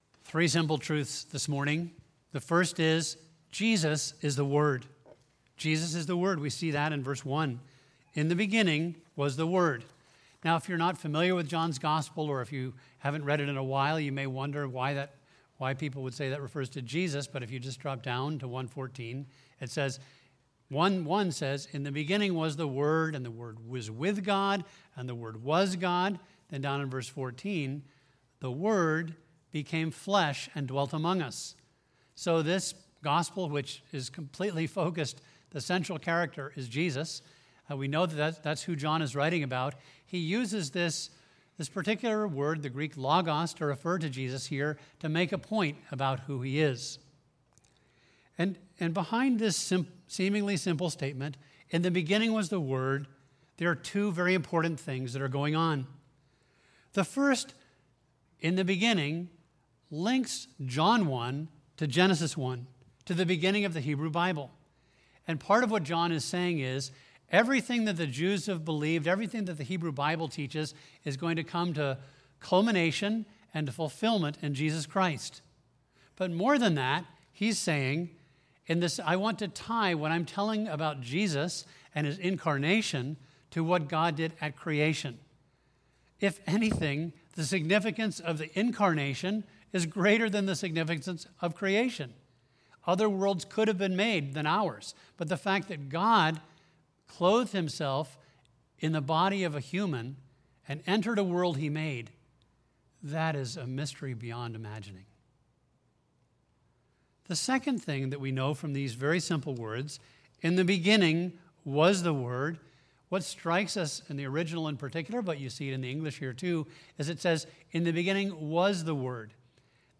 A message from the series "Gospel of John."